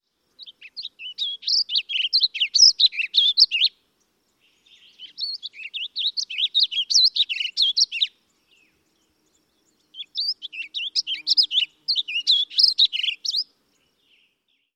typical Western song
• more “choppy” rhythm, with more varied pitch and tempo
• successive phases often very different in pitch, with abrupt changes from high to low pitch, often roughly alternating high and low phrases
• phrases average longer, with longer pauses between, but phrases often more complex so overall tempo still sounds rapid
• sounds higher-pitched, with many phrases above 4 kHz and wider range of pitch overall
• usually includes burry phrases, often many
• sounds more varied with sharp slurs and complex phrases
• only occasionally ends with a high note